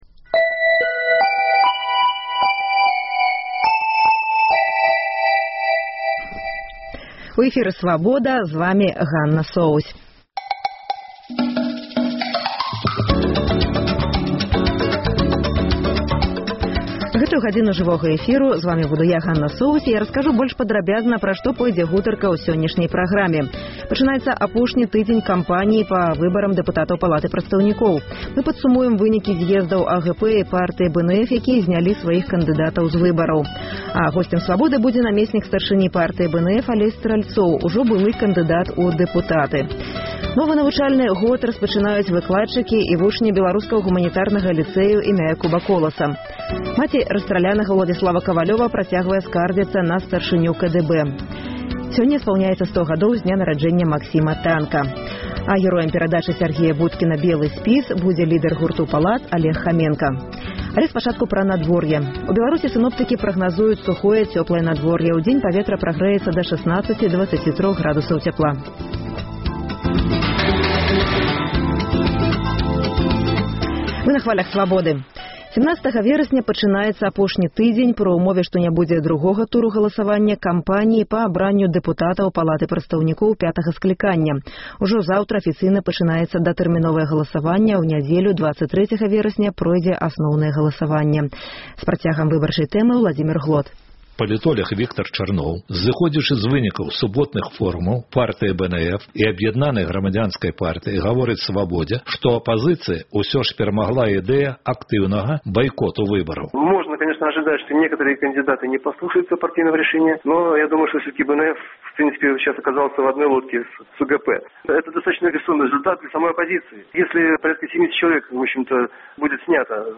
Навіны Беларусі і сьвету. Паведамленьні нашых карэспандэнтаў, званкі слухачоў, апытаньні ў гарадах і мястэчках Беларусі